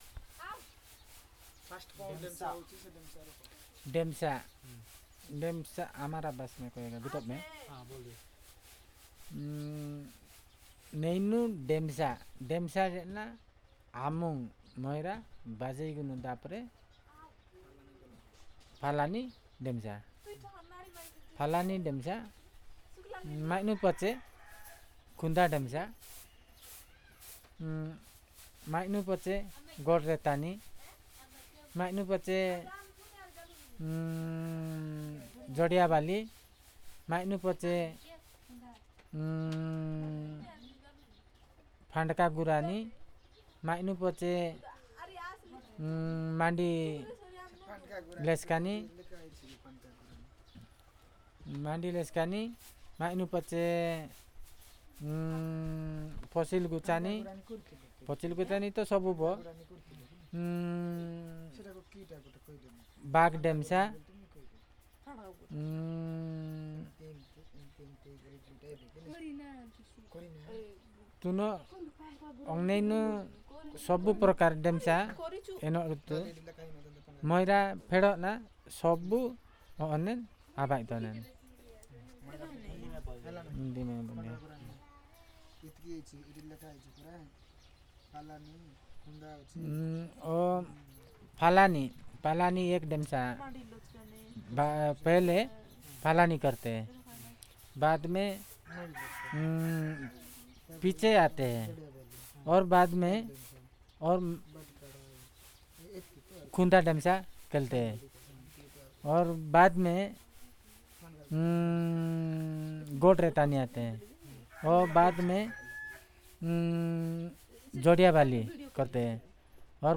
Personal narration on Demsa dance